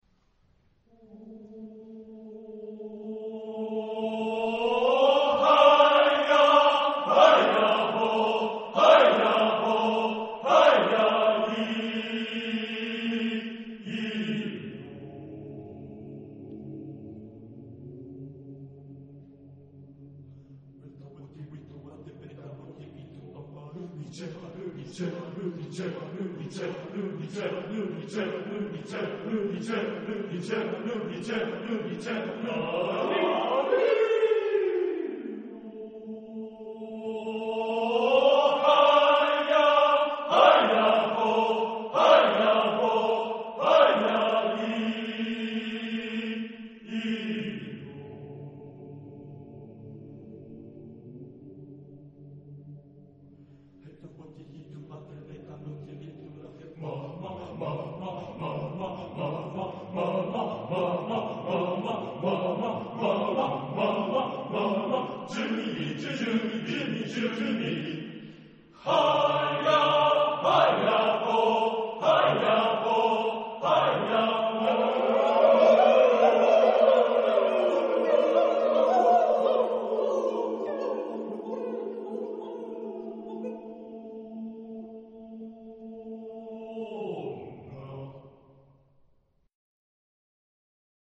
Texte en : onomatopées
Genre-Style-Forme : Profane ; Onomatopée
Type de choeur : TTBB  (4 voix égales d'hommes )
Tonalité : atonal